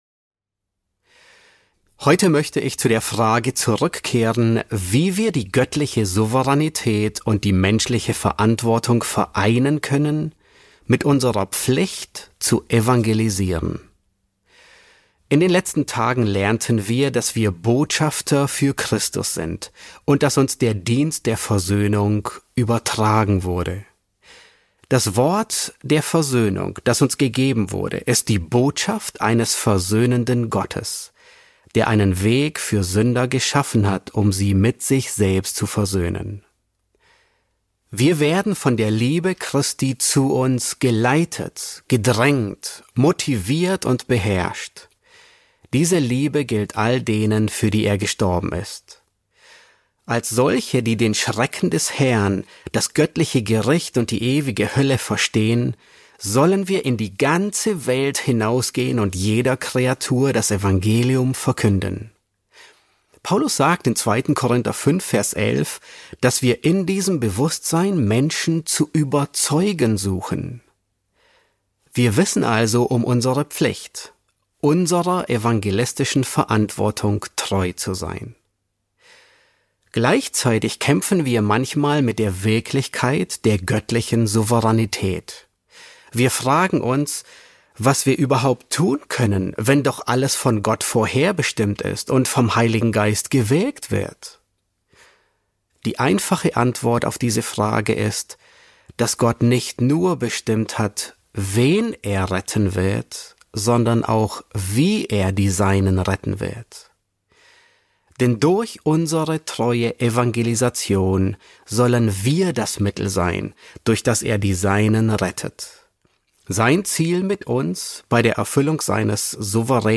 S8 F6 | Eine Erläuterung des souveränen Evangeliums ~ John MacArthur Predigten auf Deutsch Podcast